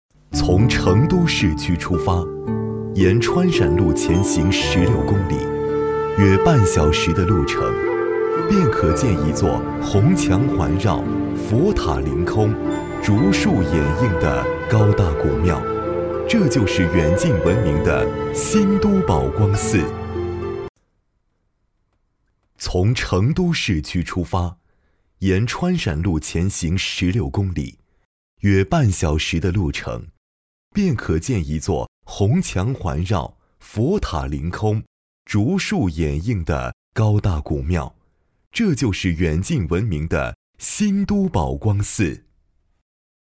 纪录片-男14-温暖叙述-宝光寺.mp3